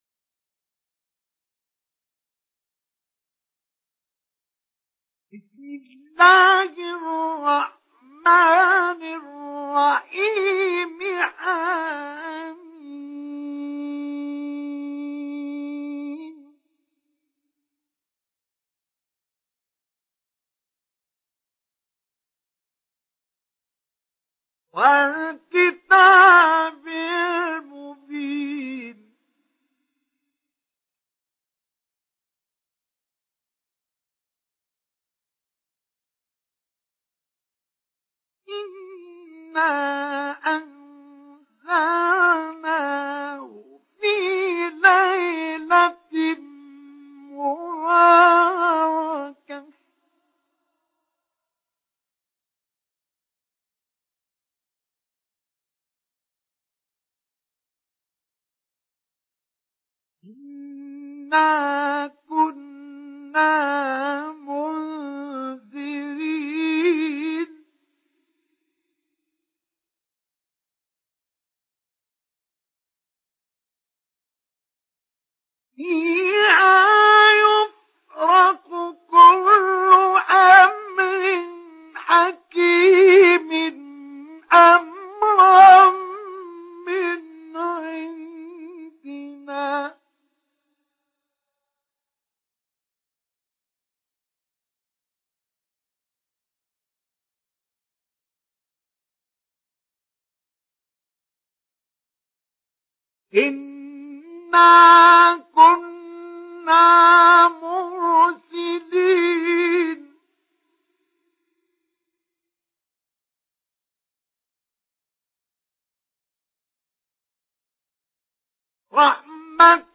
سورة الدخان MP3 بصوت مصطفى إسماعيل مجود برواية حفص